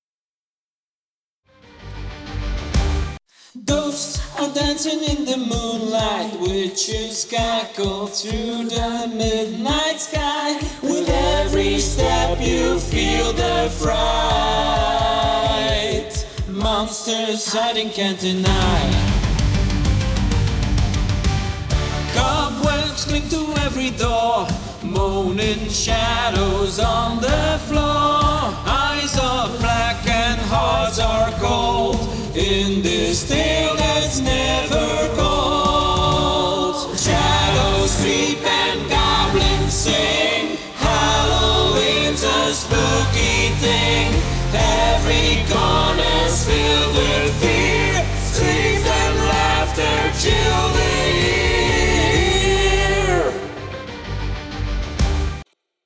Man